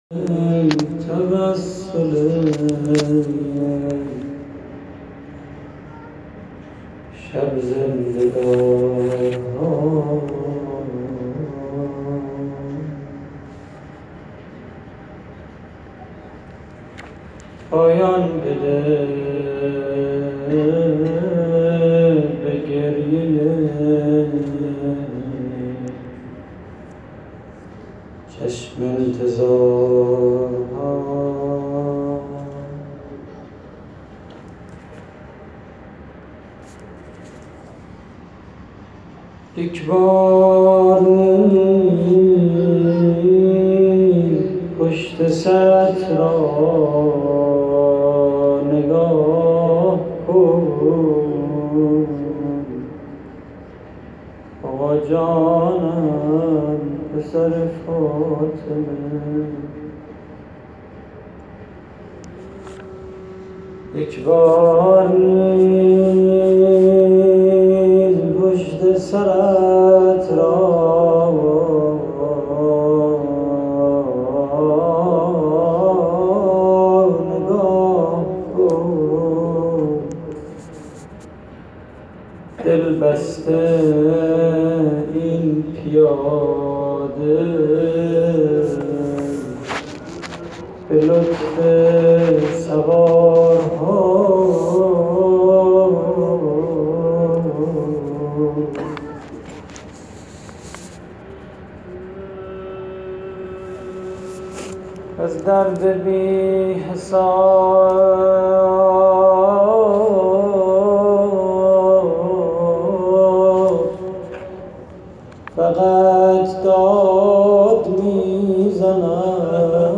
روضه سالروز تخریب بقیع / هیئت کریم آل طاها (ع)